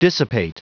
Prononciation du mot dissipate en anglais (fichier audio)
Prononciation du mot : dissipate